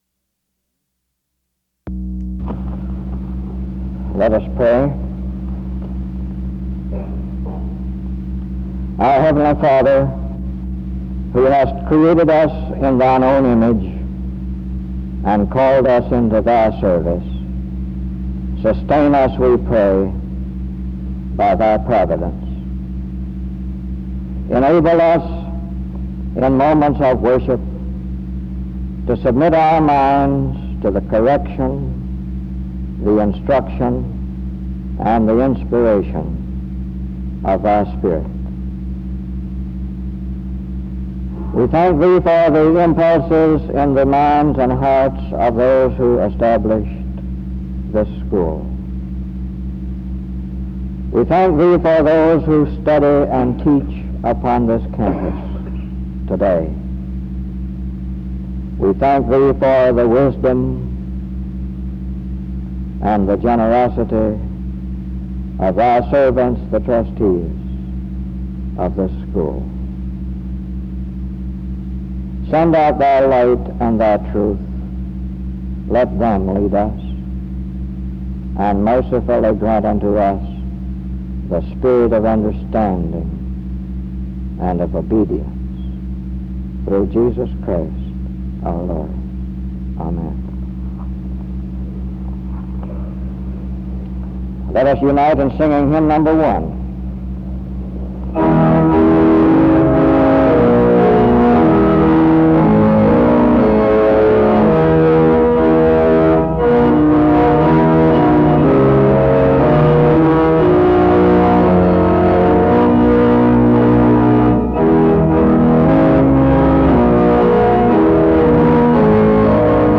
The service begins with a prayer from 0:00-1:30. The chapel sings a hymn from 1:34-4:56. The trustees are recognized and welcomed from 5:07-6:49. Music plays from 7:00-10:06. An introduction to the speaker is given from 10:18-13:01. The theme of his message was “A Dream and Truth.” He speaks from 13:07-36:51. The chapel sings the seminary hymn from 36:53-40:28. A closing prayer is offered from 40:35-43:26. The service closes with music from 43:26-44:37.